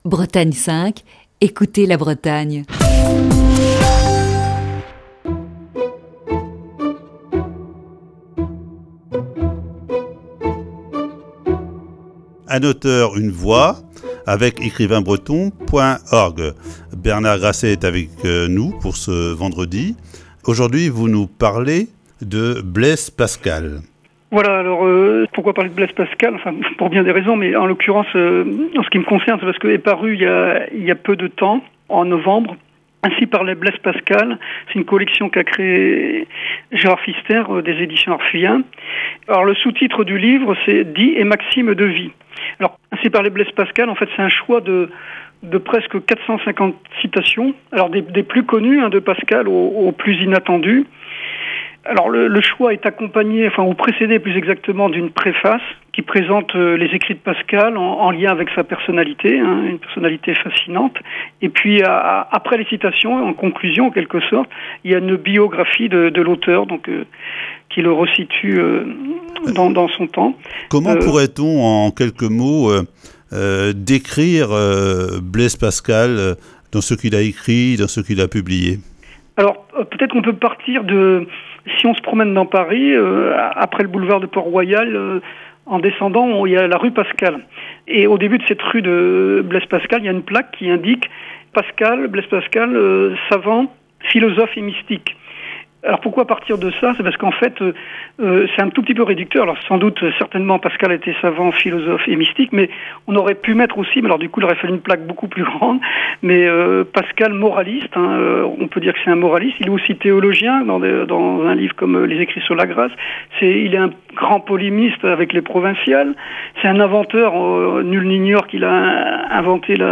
Il nous propose d'écouter ce vendredi la cinquième et dernière partie de cette série d'entretiens.